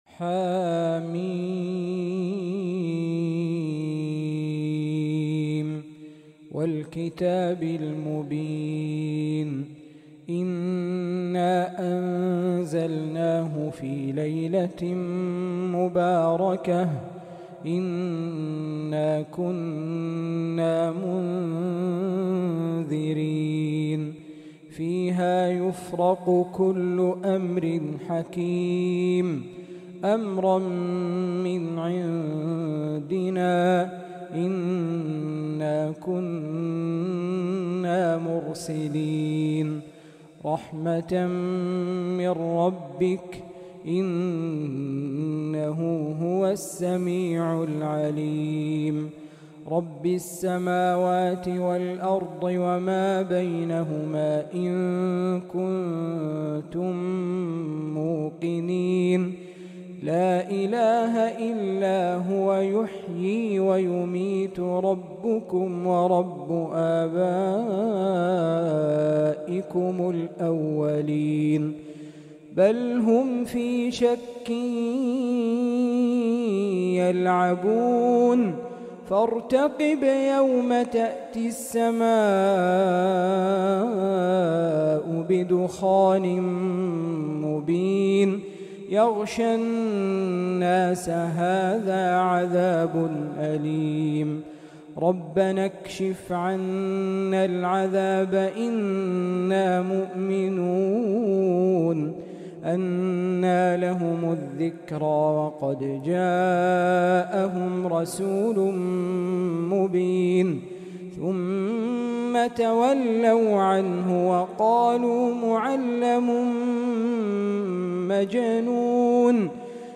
فجرية هادئة خاشعة مميزة